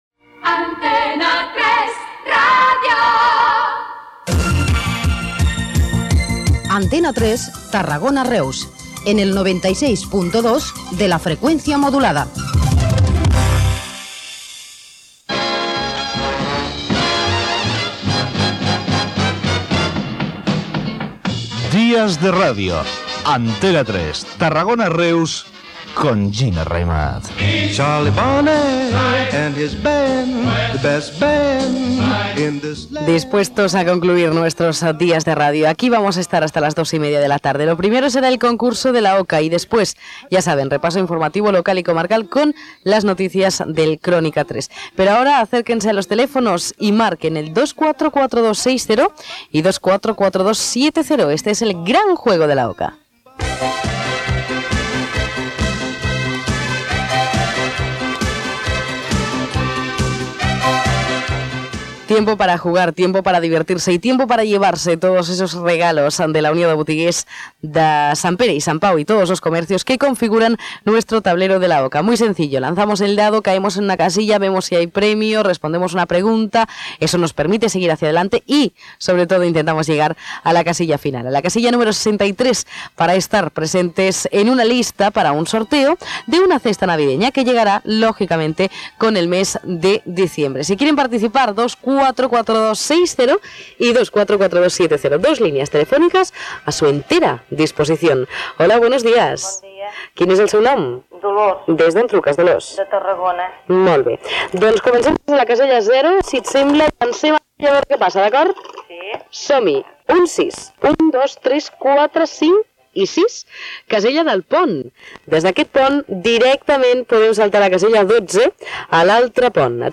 Indicatius de l'emissora i del programa, propers continguts, telèfon de participació, "El gran juego de la oca", trucada telefònica
Entreteniment
FM